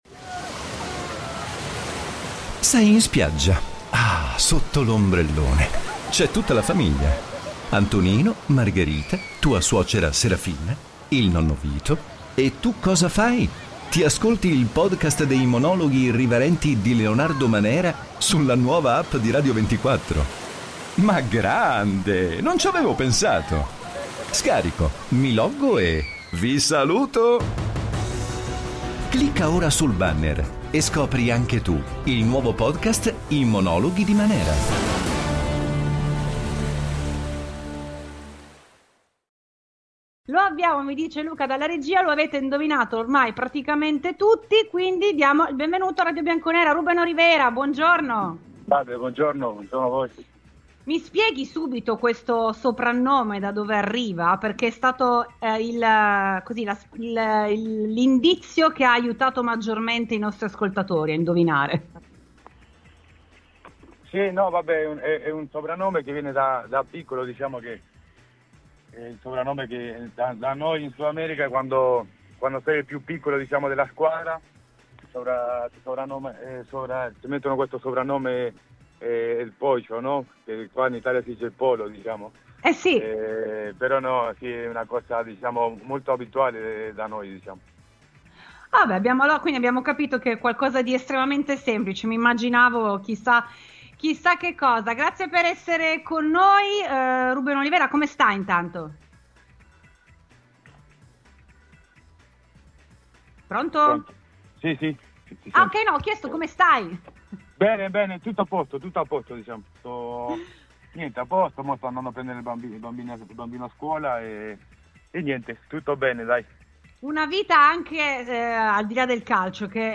Lo abbiamo intervistato in esclusiva su Radiobianconera durante RBN Cafè.